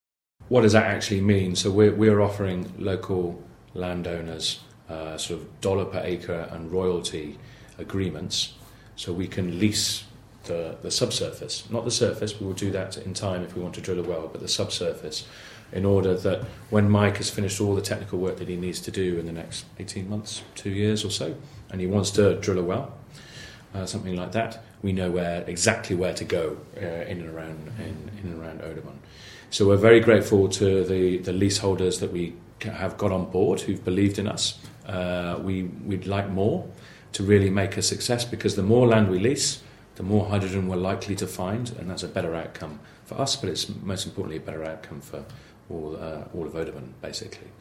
Representatives from Snowfox Discovery, a natural hydrogen exploration company, appeared before the Audubon County Board of Supervisors on Tuesday afternoon to outline their plans.